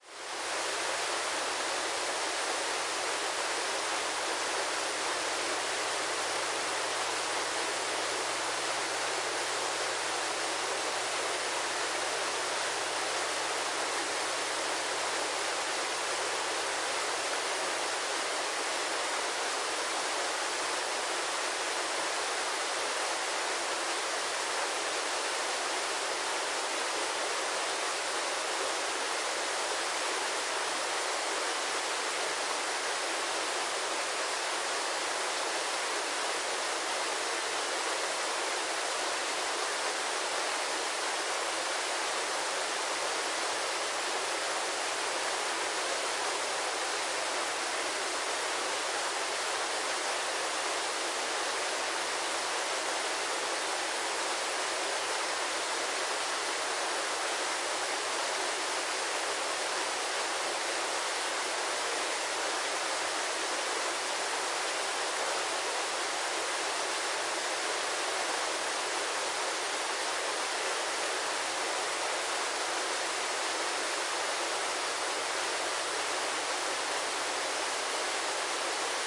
现场记录。水 " 从上面看大瀑布
描述：使用内置麦克风的Zoom H1录制的Wav文件。瀑布在从瀑布上面记录的森林里。在提契诺（Tessin），瑞士。
Tag: 瑞士 fieldrecording 瀑布 和平 自然 放松 fieldrecording 落下 环境 流动 以上 森林 下降 提契诺 环境 森林 河流 小溪 提契诺